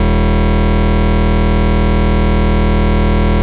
You can draw waveforms easily in Renoise.